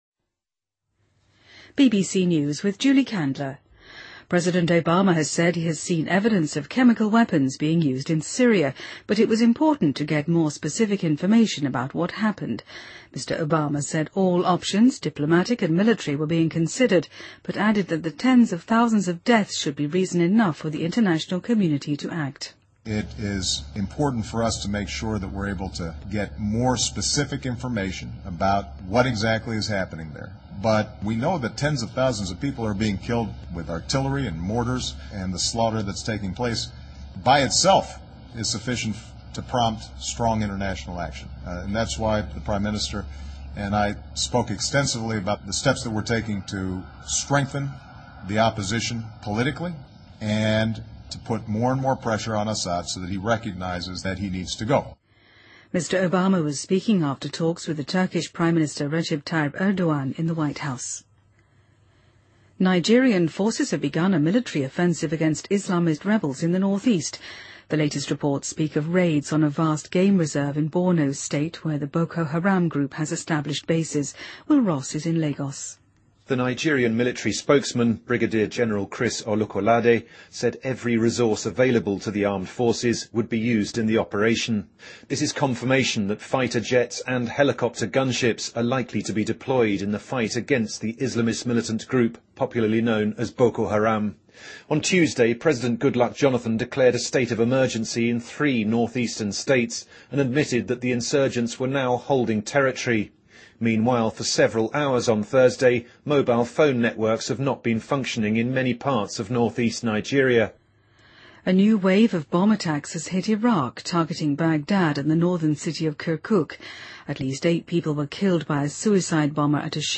BBC news,2013-05-17